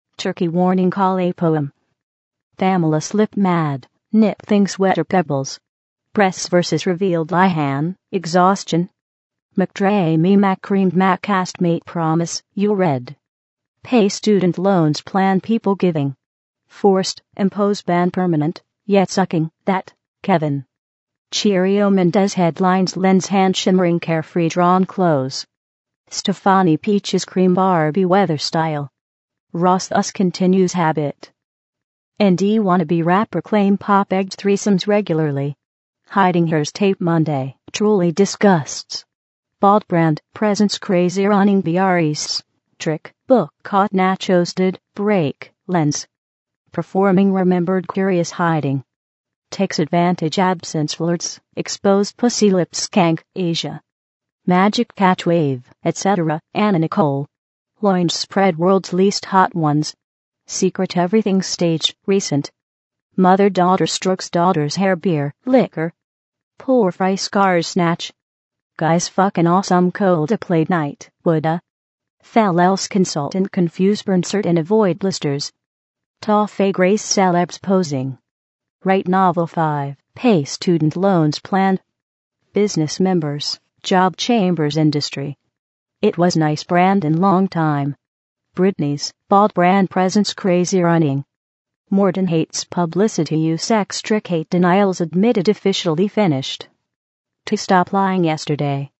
i took the body text from a number of spam emails, you know the kind that sends randomly generated texts with advert attachments, well, i took those and made a randomly generated poem by mixing the lines together. then i ran it through at&t's true voice synthesizer.